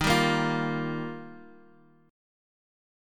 D#add9 Chord
Listen to D#add9 strummed